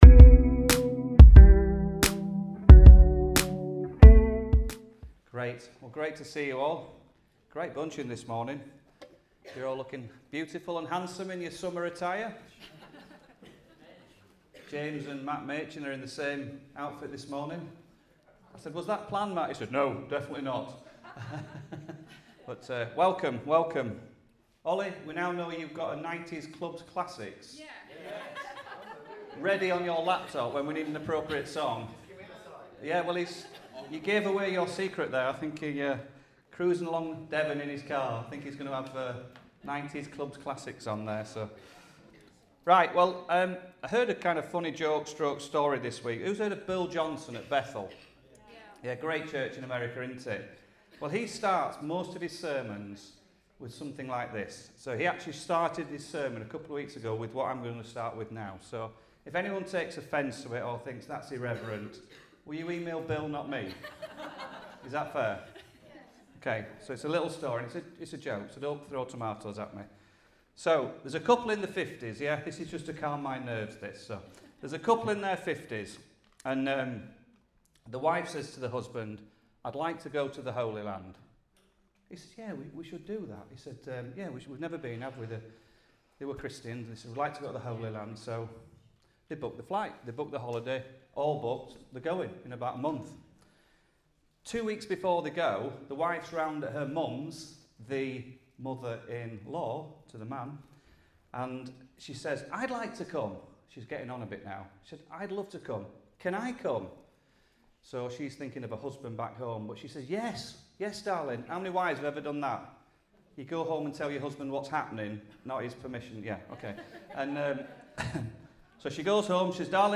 Sunday Messages A Discipleship Series